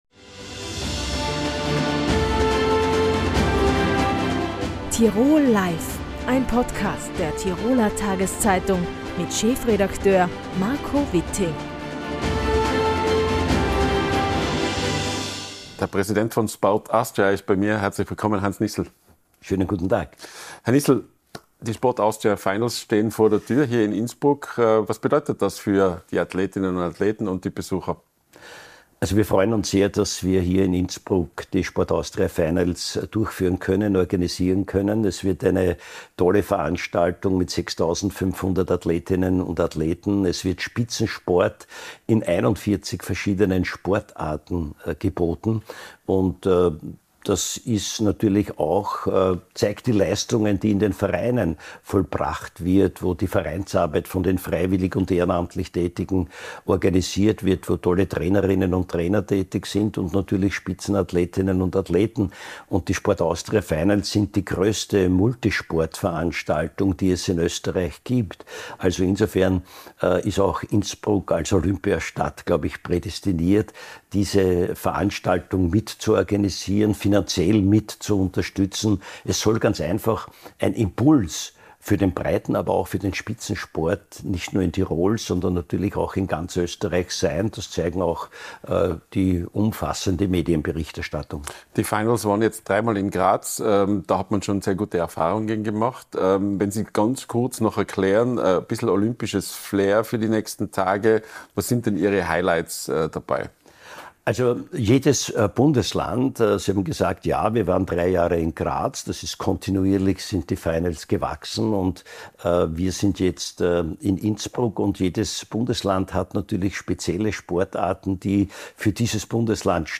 Im Tirol-Live-Gespräch